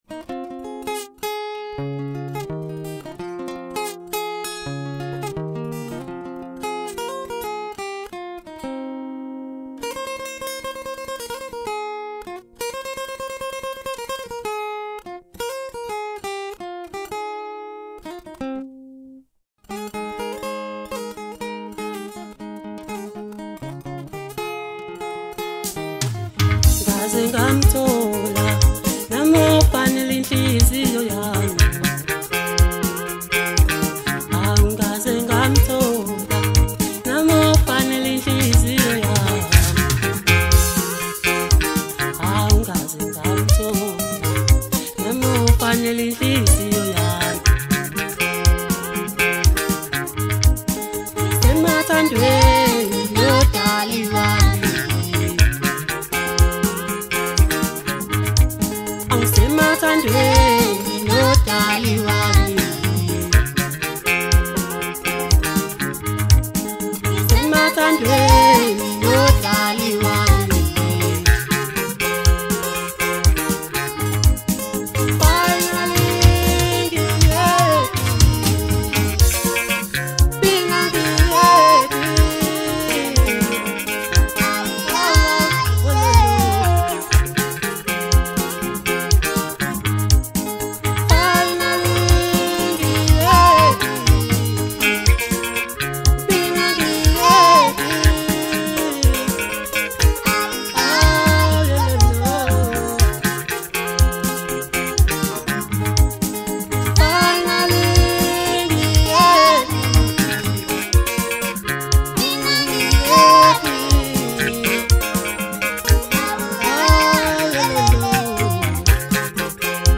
• Genre: Maskandi